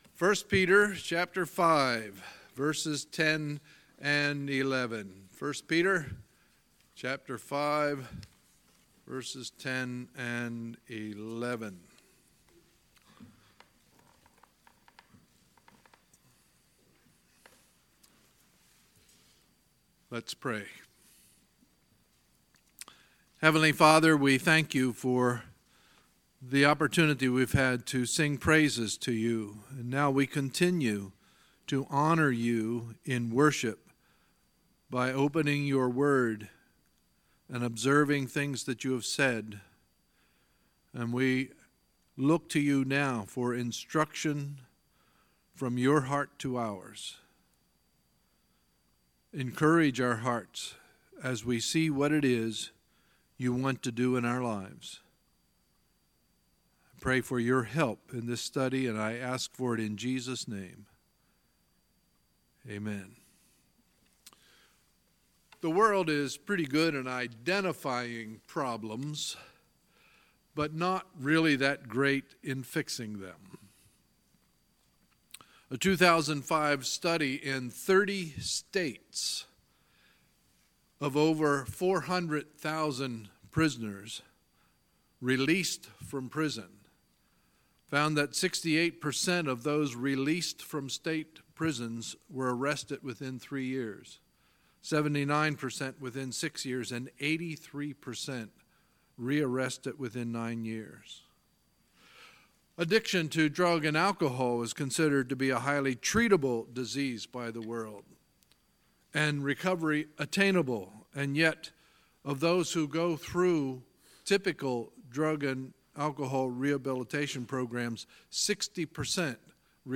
Sunday, August 19, 2018 – Sunday Morning Service